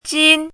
chinese-voice - 汉字语音库
jin1.mp3